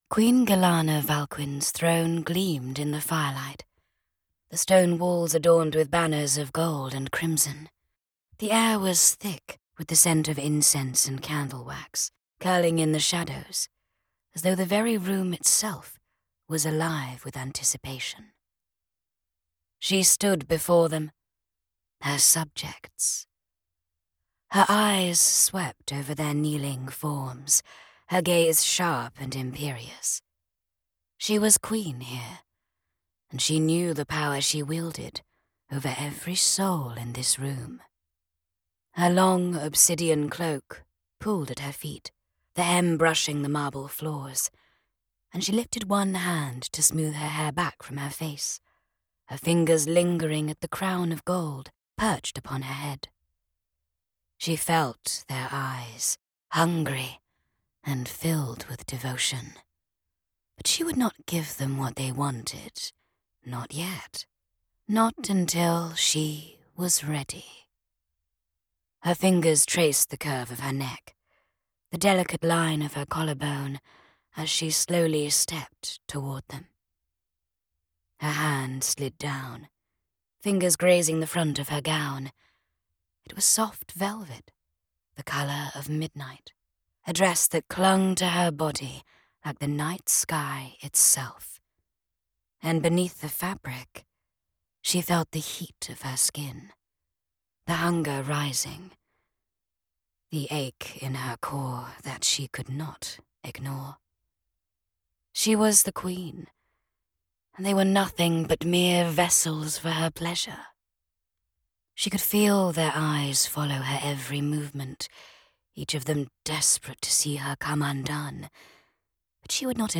3RD PERSON FANTASY ROMANCE, F, BRITISH RP
Custom built broadcast quality studio